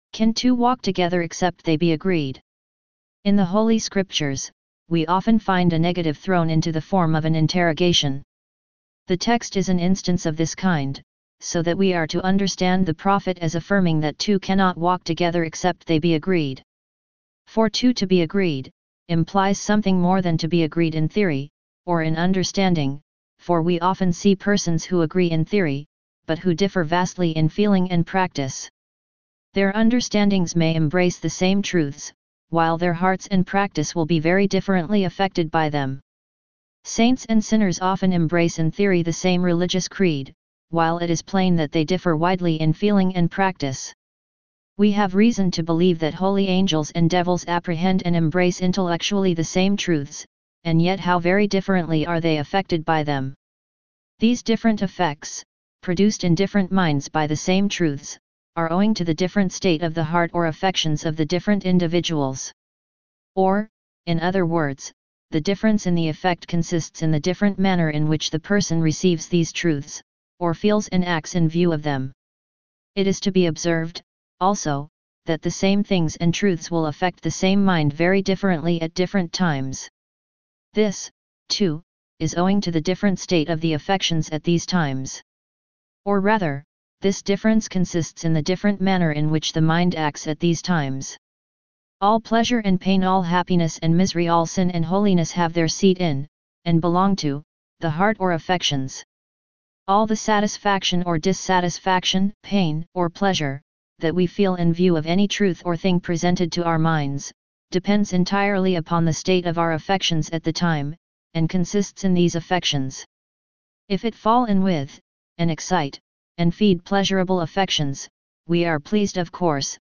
.The is a lecture by Charles Finney originally titled with the verse below.